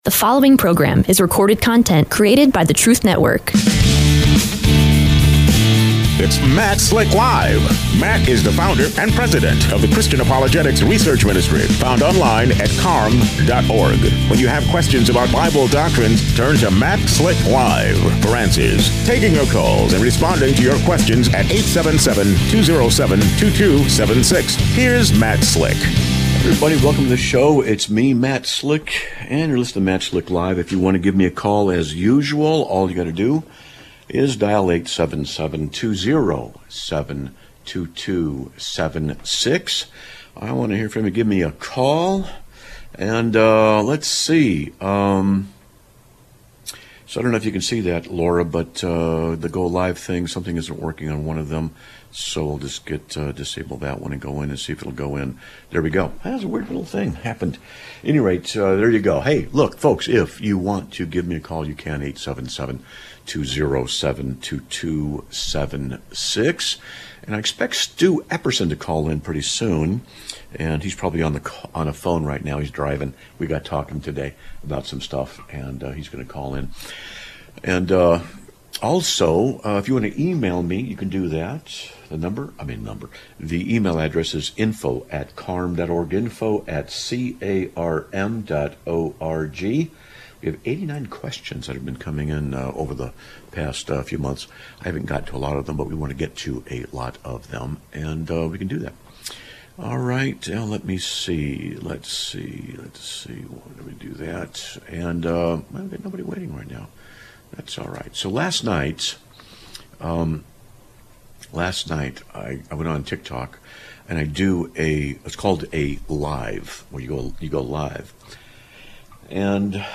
Live Broadcast of 09/03/2025